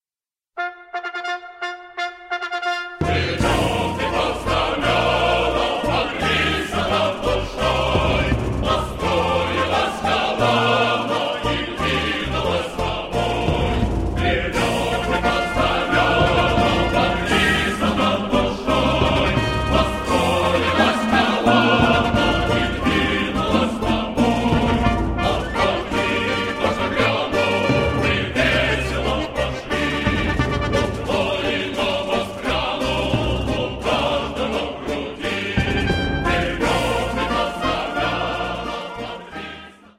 мужской хор
Исторические военные песни
• «Атака» (напев Хорватского марша) 2:05